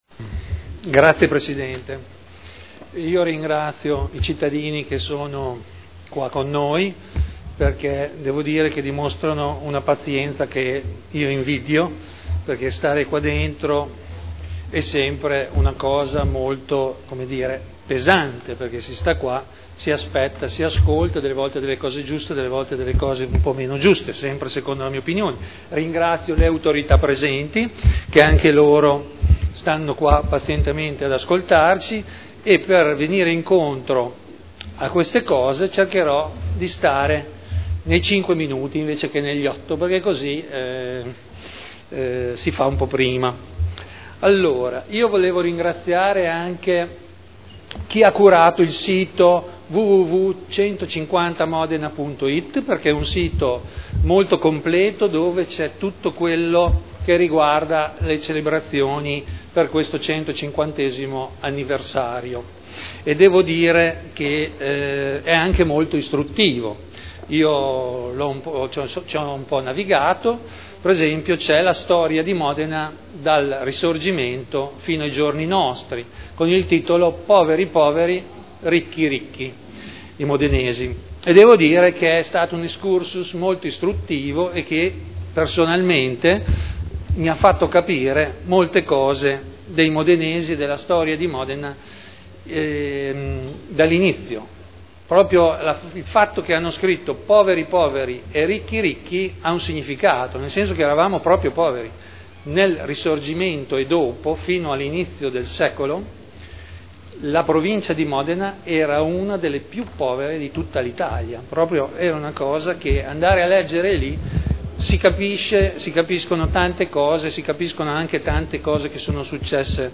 Seduta solenne 150° anniversario dell'unità d'Italia. Interventi dei gruppi consiliari